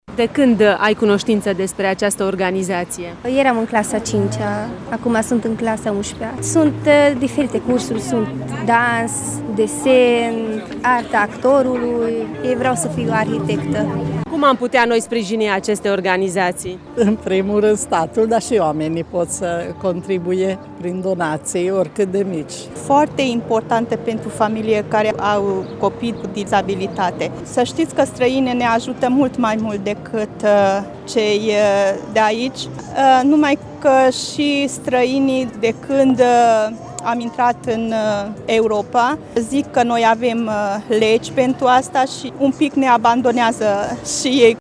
De cealaltă parte, au participat la târg și beneficiari – persoanele recunoscătoare fundațiilor mureșene: